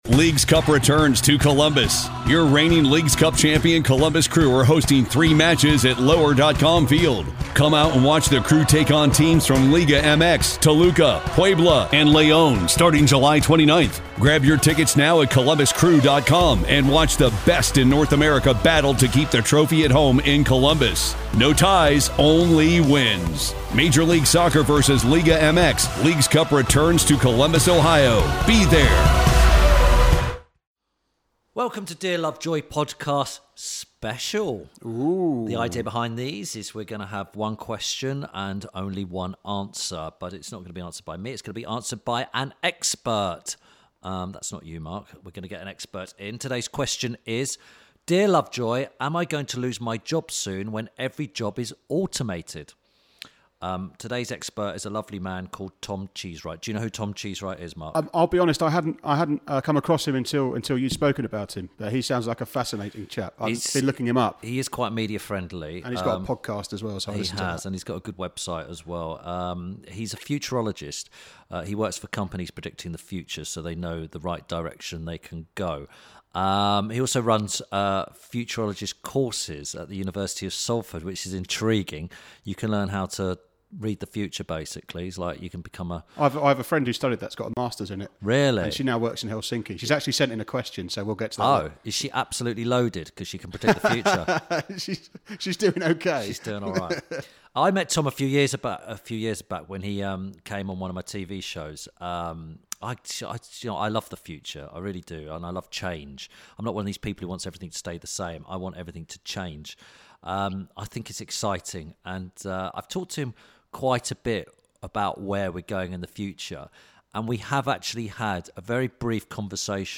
INTERVIEW SPECIAL
In a break in Dear Lovejoy tradition Tim Lovejoy is sat opposite someone who knows what they’re talking about.